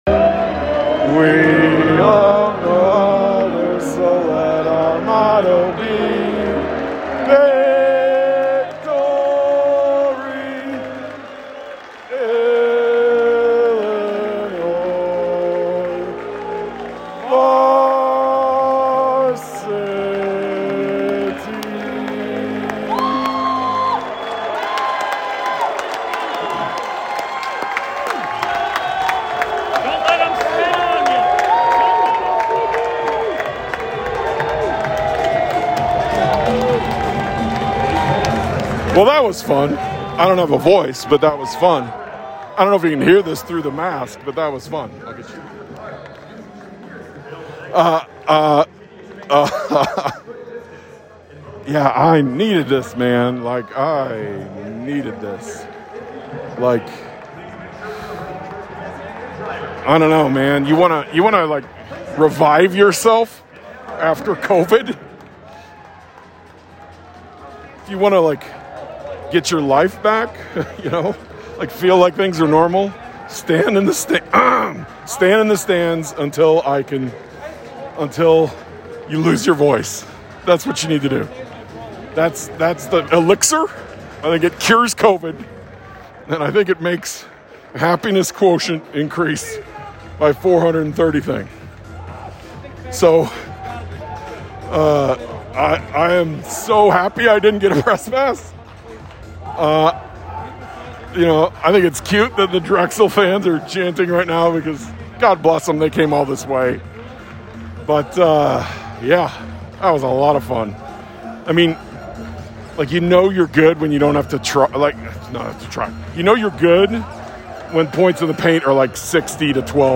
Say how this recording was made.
Headliner Embed Embed code See more options Share Facebook X Subscribe (Bonus episode) From The Stands at Farmer’s Coliseum (with no voice, a mask, and loud music).